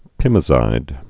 (pĭmə-zīd)